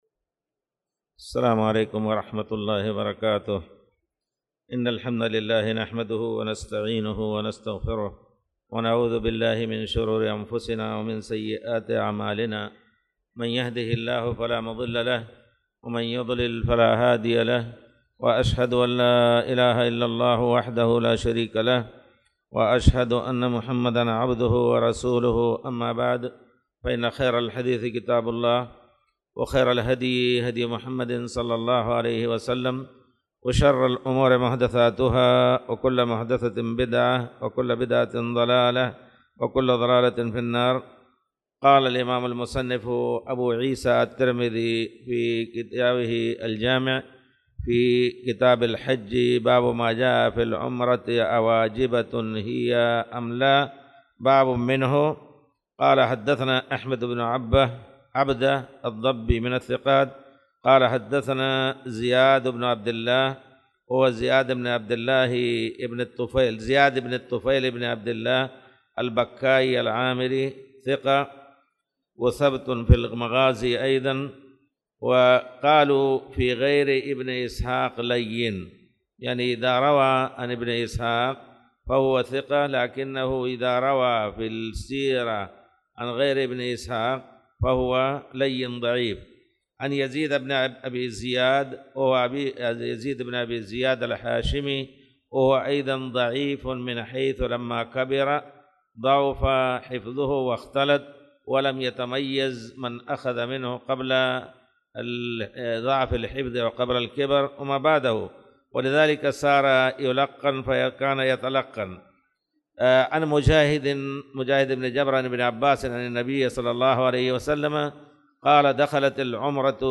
تاريخ النشر ١٢ شعبان ١٤٣٧ المكان: المسجد الحرام الشيخ